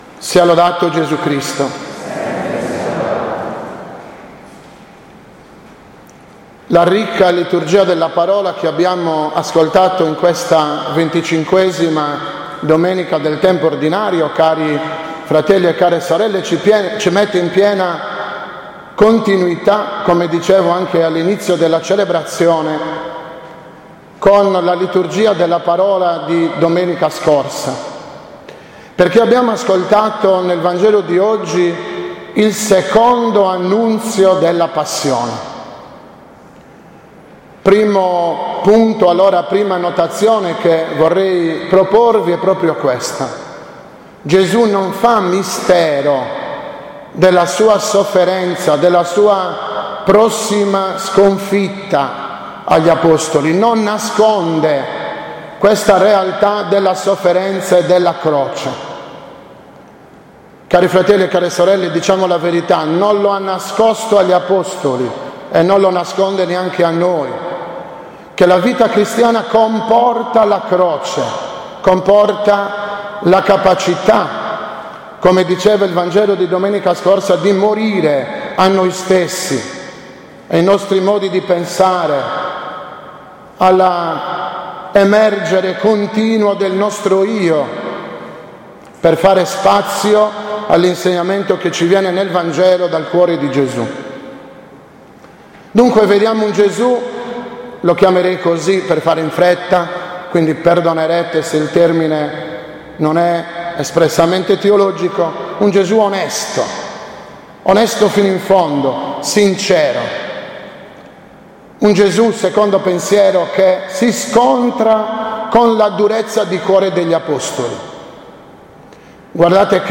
20.09.2015 – OMELIA DELLA XXV DOMENICA DEL TEMPO ORDINARIO